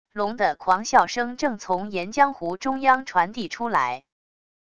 聋的狂笑声正从岩浆湖中央传递出来wav音频生成系统WAV Audio Player